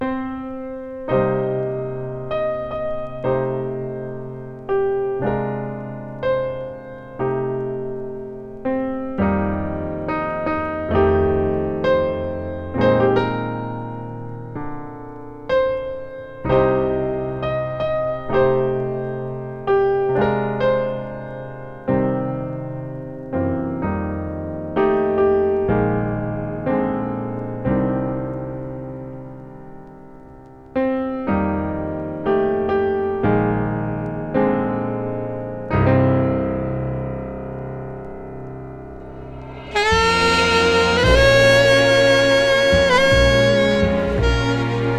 Jazz, Stage & Screen, Soundtrack　USA　12inchレコード　33rpm　Stereo